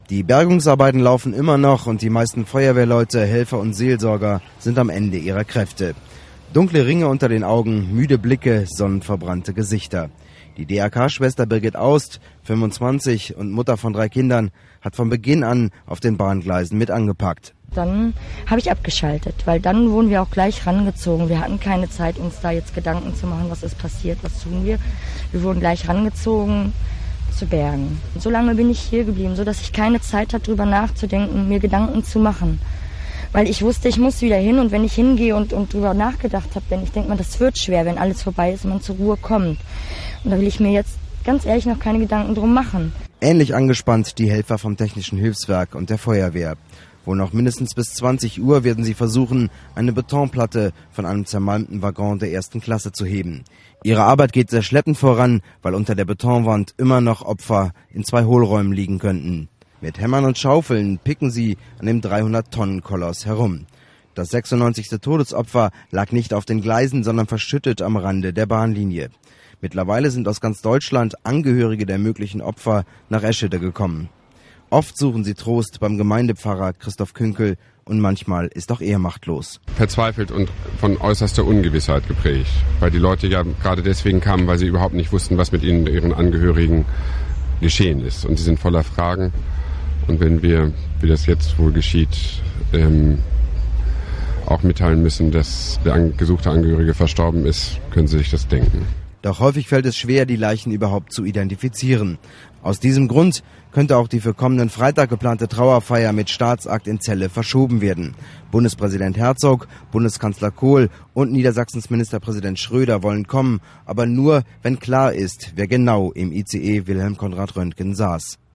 er an der Unglückstelle die aktuelle Situation zusammenfasst.